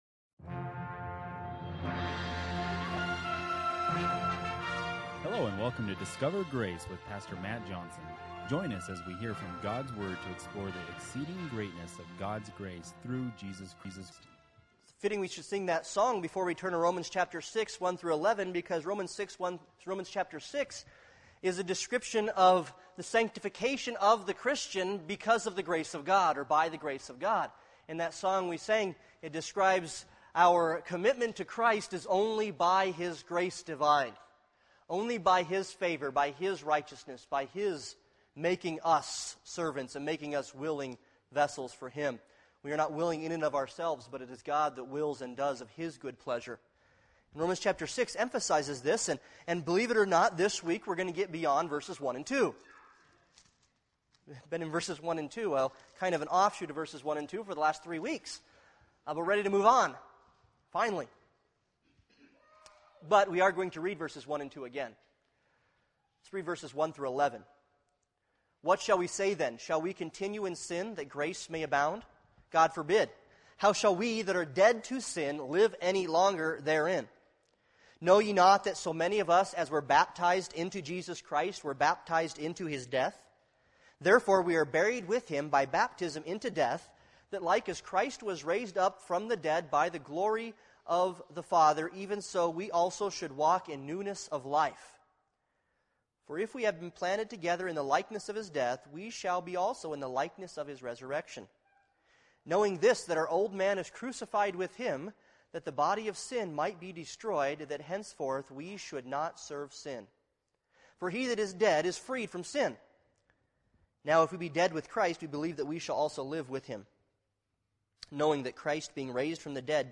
Romans 6:1-11 Service Type: Sunday Morning Worship « Living By Grace